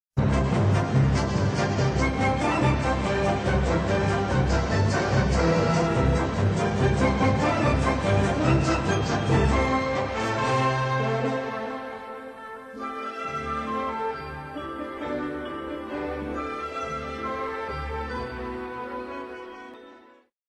Orchestersuite in 3 Sätzen (leichte Sinfonik)